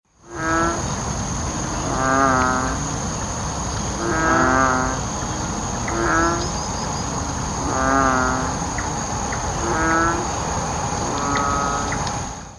Play call
Its call is a loud, cattle-like bellow, which can be heard after heavy rain has created flooded pools in which the frogs assemble.